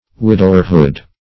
Widowerhood \Wid"ow*er*hood\, n. The state of being a widower.